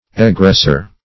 Egressor \E*gress"or\, n. One who goes out.